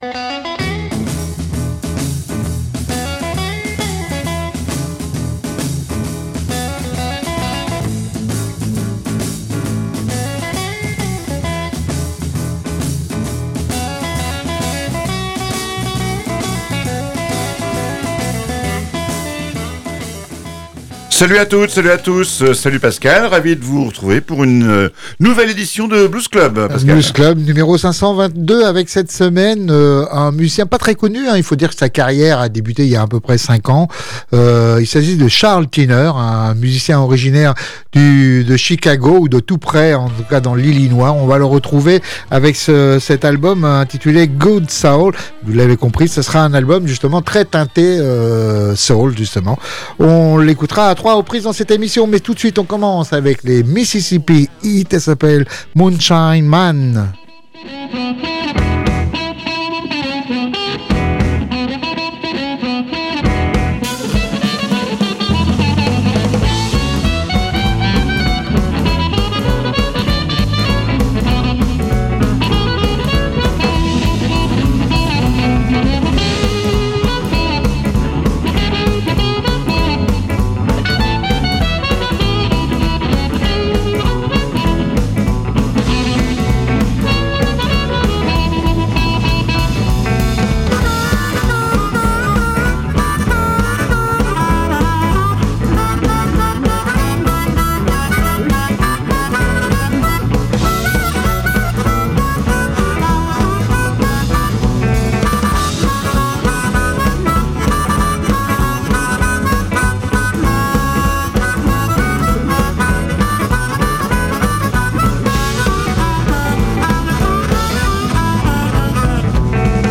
joue passionnément du piano et de l’orgue
chante avec l’âme d’un bluesman et le feu d’un prédicateur
blues soulful